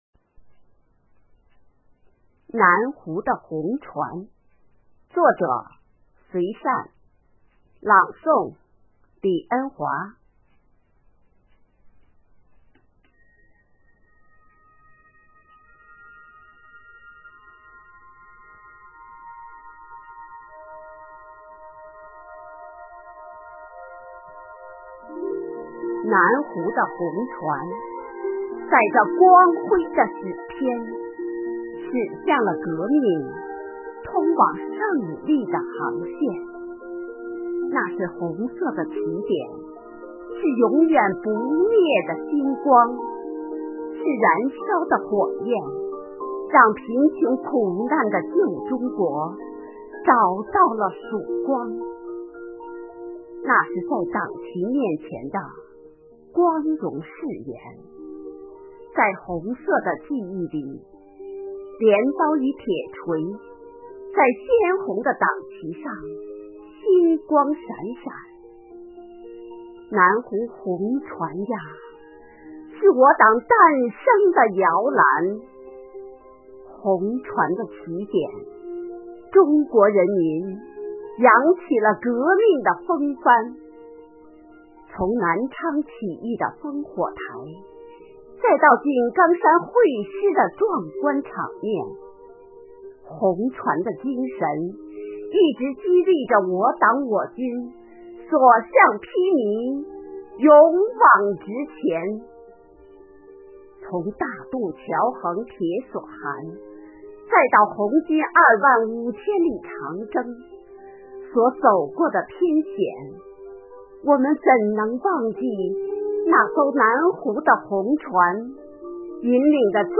“党在我心中，喜迎二十大”主题朗诵会